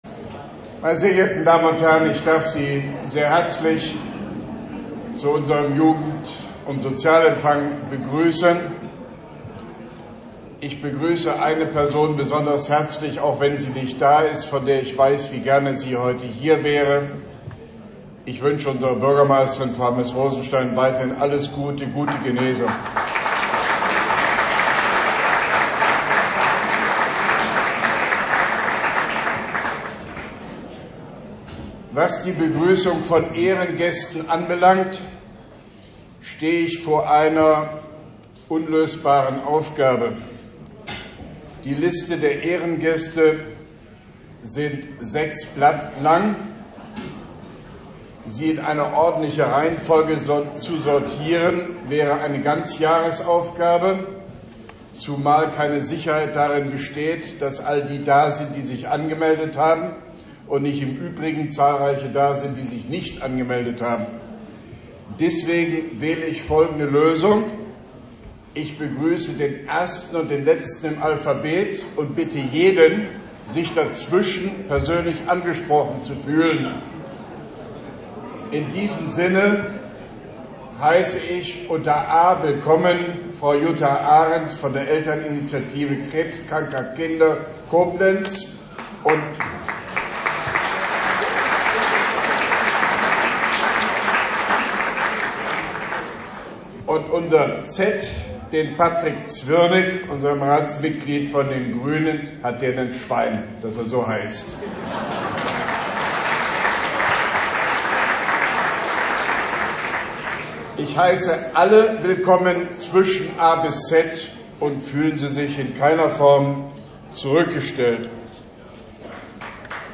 Grußwort von OB Hofmann-Göttig beim Jugend- und Sozialempfang der Stadt Koblenz und Verleihung der Ehrennadel, Koblenz 18.01.2018